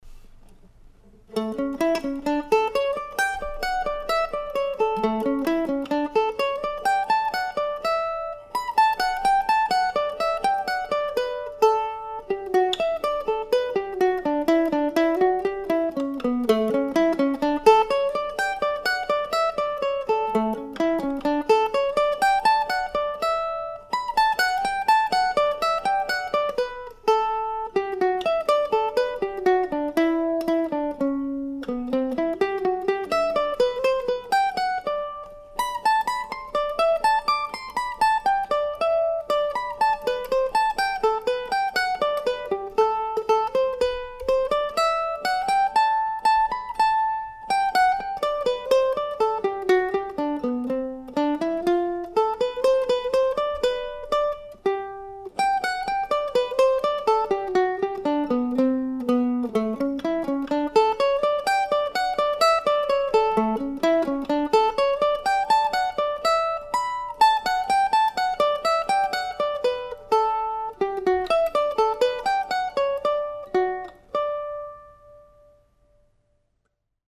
solo mandolin